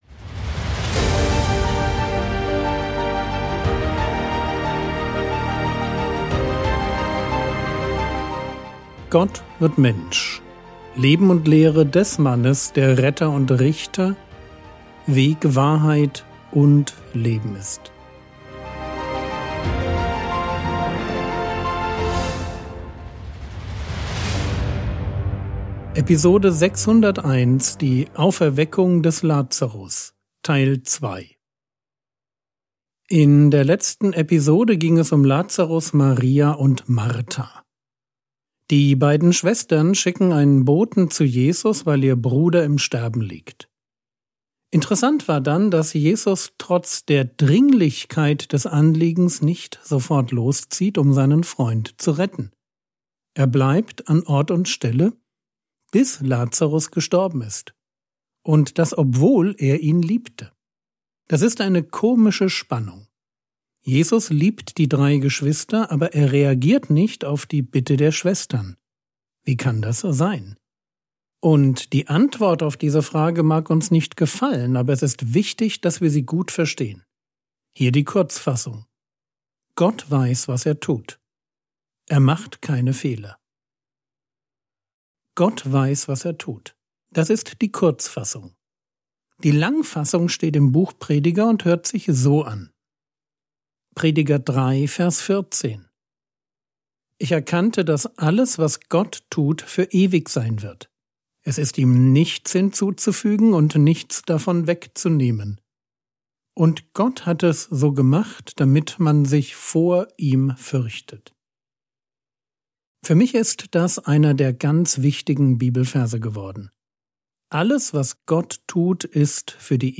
Episode 601 | Jesu Leben und Lehre ~ Frogwords Mini-Predigt Podcast